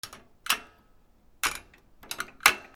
金属カチャカチャ
/ M｜他分類 / L01 ｜小道具 / 金属
『カチャ』